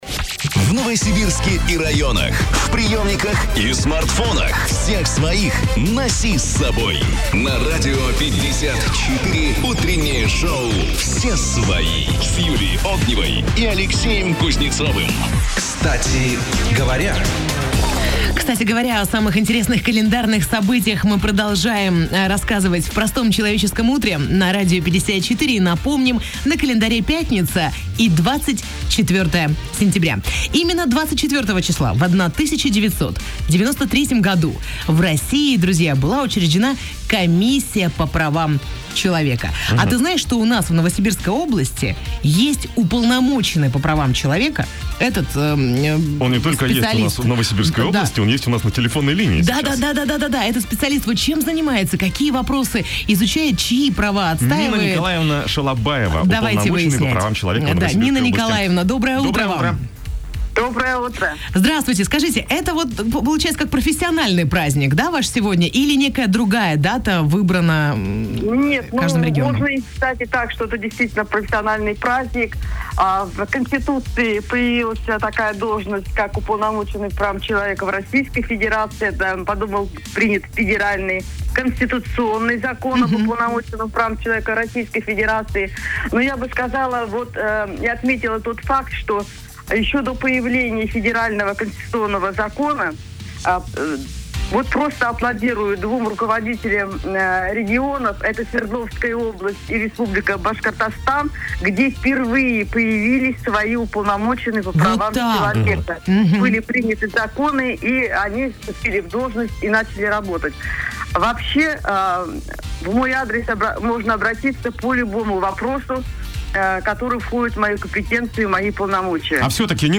В эфире утренней программы «Кстати говоря» на Радио 54 Нина Шалабаева ответила на вопросы журналистов. 24 сентября 1993 года в России была основана Комиссия по правам человека - совещательный и консультативный орган при Президенте страны.
intervyu_s_upolnomochennym_po_pravam_cheloveka.mp3